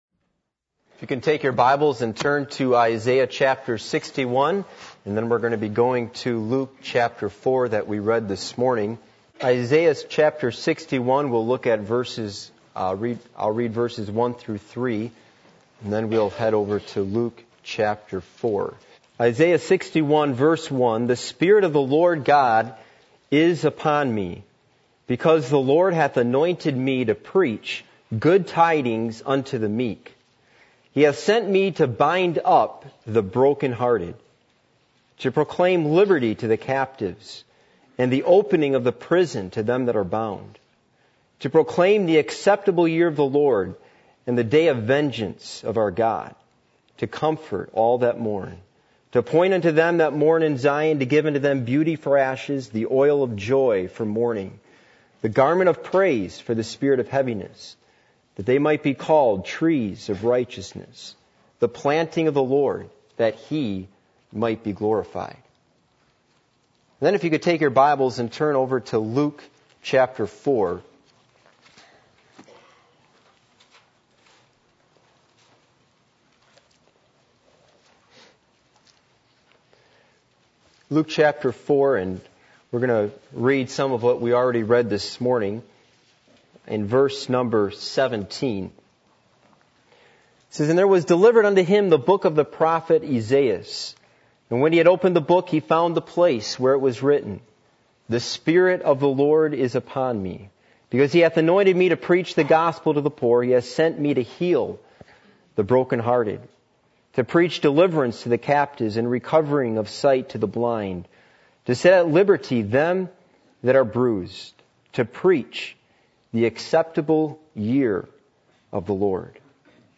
Luke 4:17-18 Service Type: Sunday Morning %todo_render% « Why Rock Music Is Not Acceptable For Worship What Are You Doing For Christ?